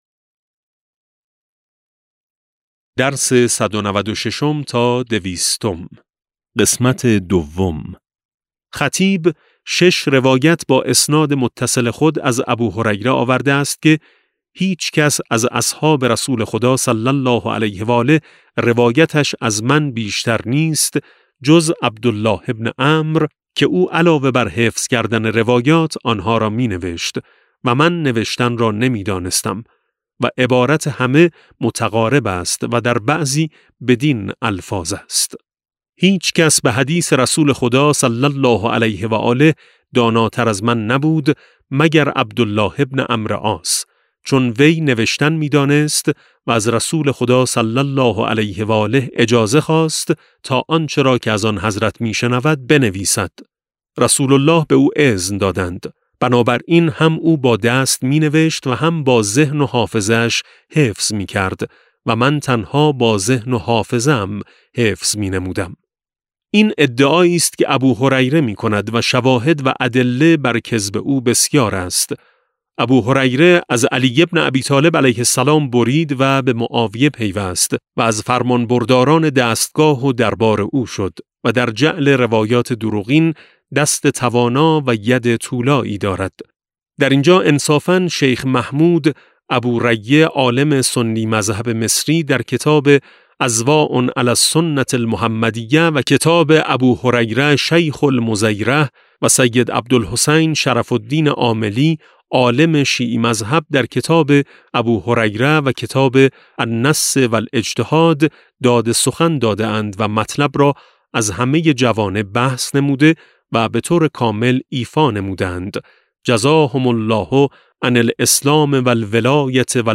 کتاب صوتی امام شناسی ج14 - جلسه2